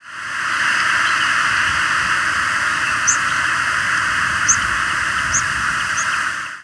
Blue-winged Warbler diurnal flight calls
Diurnal calling sequences:
Bird in short flight with Northern Mockingbird singing in the background.